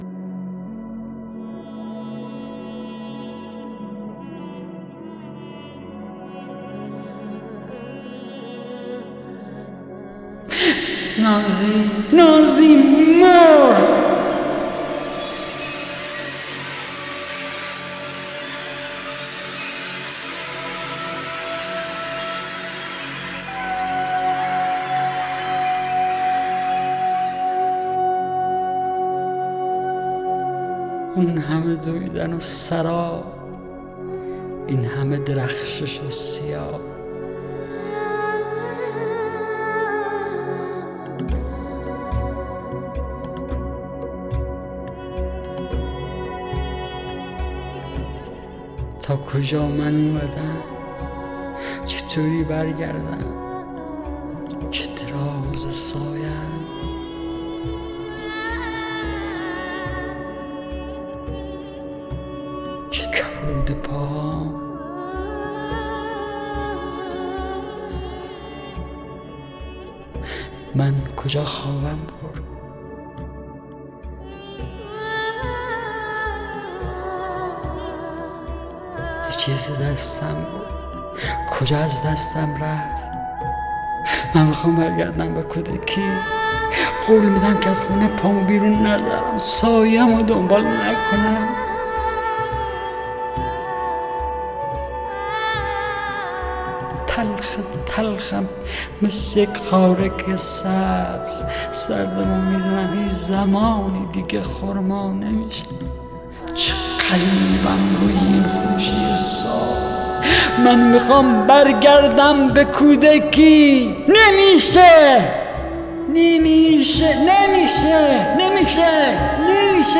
دانلود دکلمه نازی با صدای حسین پناهی
گوینده :   [حسین پناهی]